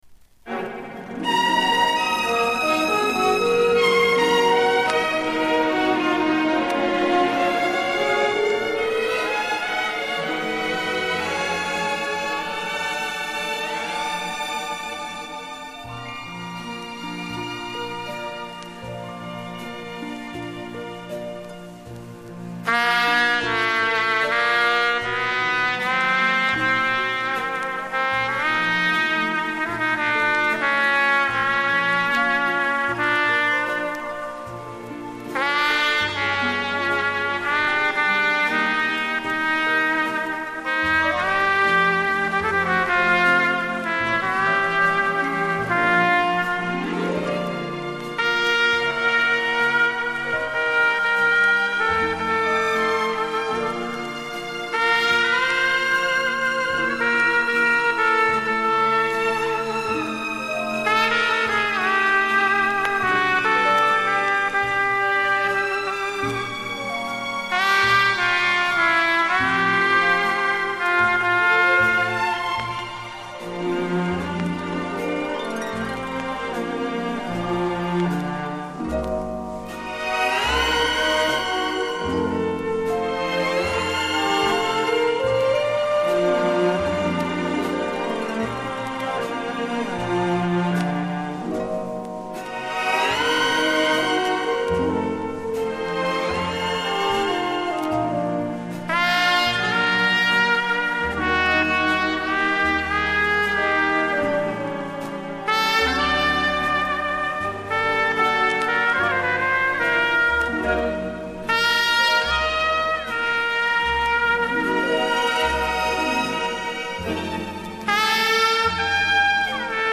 в стиле "ballada" со струнным оркестром